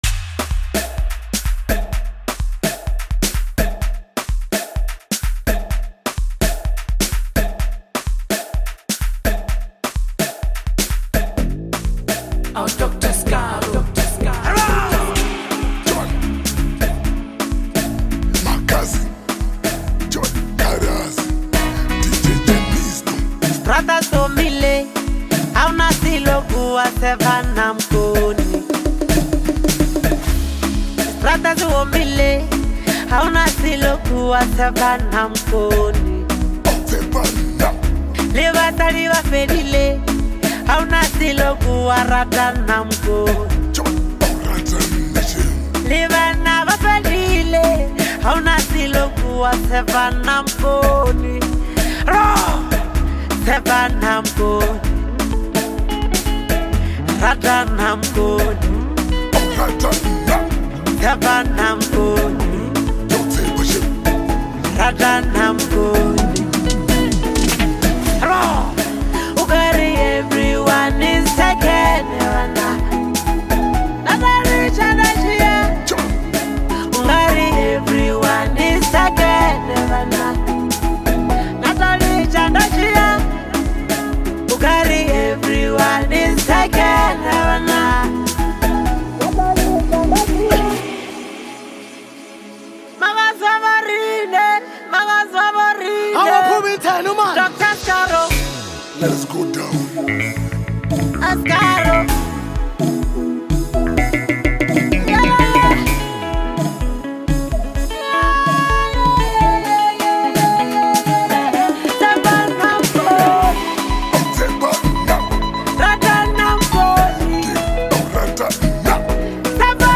DANCE Apr 07, 2026